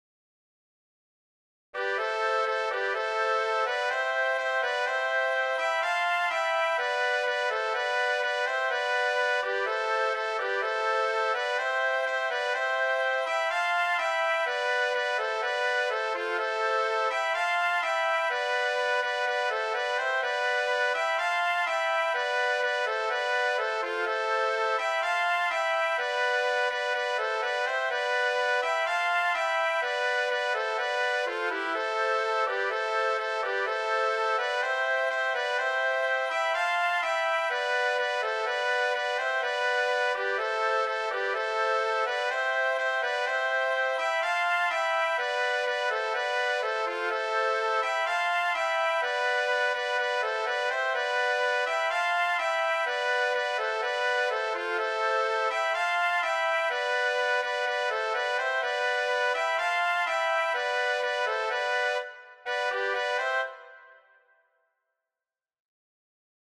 Origine EuropeEurope > FranceFrance > BretagneBretagne
Genre Scottish
Instruments 2 flûtes, hautbois, clarinette, trompette
Tonalité La mineur
Rythme 4/4
Tempo ♩=125